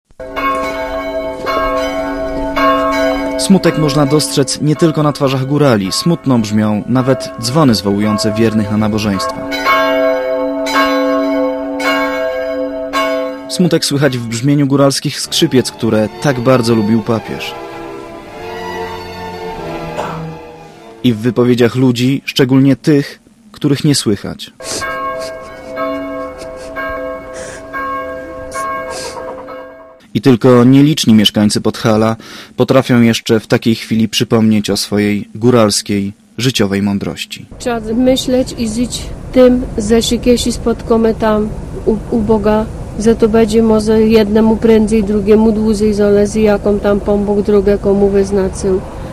Po śmierci ukochanego Ojca Świętego płacze cale Podhale. W Zakopanem panuje absolutna cisza i wyjątkowe skupienie.
Relacja